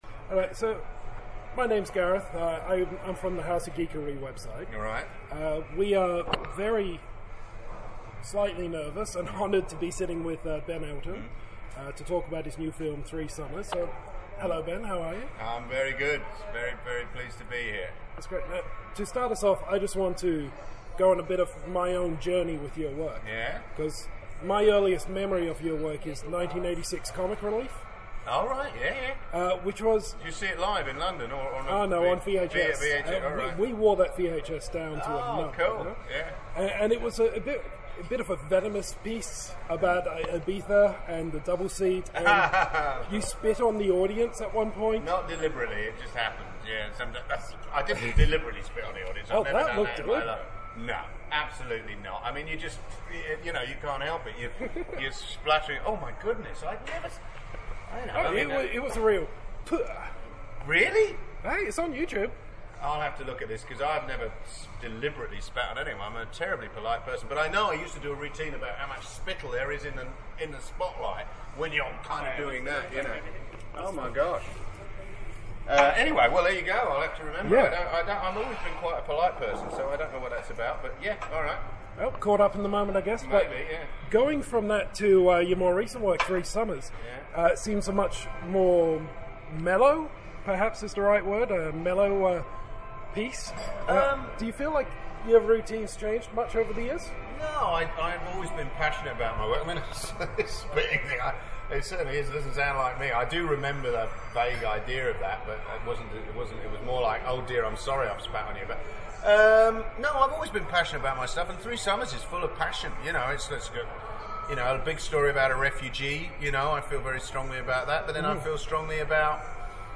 Exclusive Interview with Ben Elton!
So, as you imagine, sitting down with the man himself for a coffee and a chat was a particularly special moment.
And now we have our exclusive interview with the writer and director of the new comedy Three Summers: Ben Elton.
ben-elton-interview-house-of-geekery.mp3